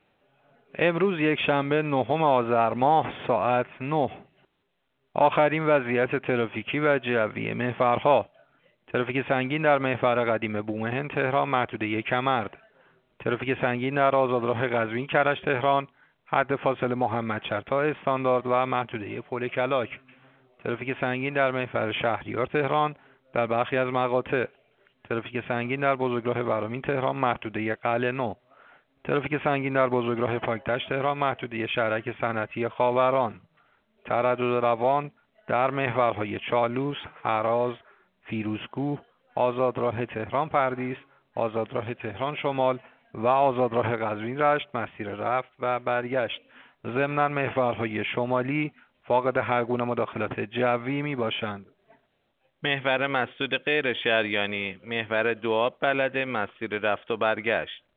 گزارش رادیو اینترنتی از آخرین وضعیت ترافیکی جاده‌ها ساعت ۹ نهم آذر؛